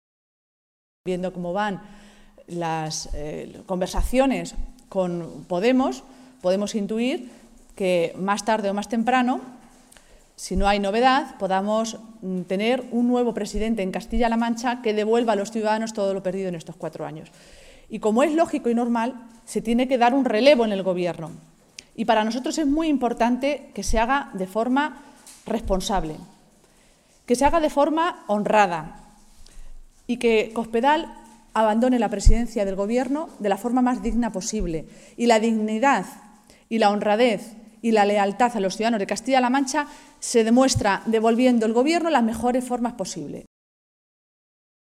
Maestre se pronunciaba de esta manera esta mañana, en Toledo, en una comparecencia ante los medios de comunicación en la que, además de asegurar que se va a estudiar la legalidad de esas decisiones, las ha definido como “poco éticas”.
Cortes de audio de la rueda de prensa